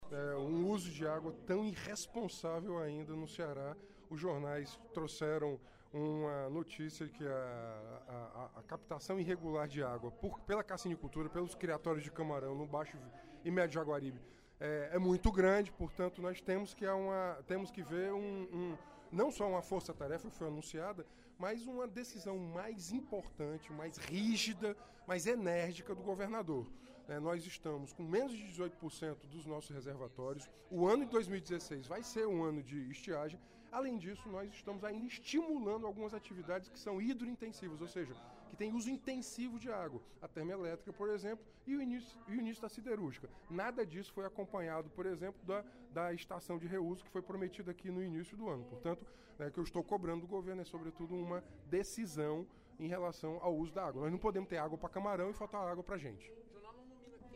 O deputado Renato Roseno (Psol) denunciou, durante o primeiro expediente da sessão plenária desta quinta-feira (03/09), a captação irregular de água doce por criatórios de camarão.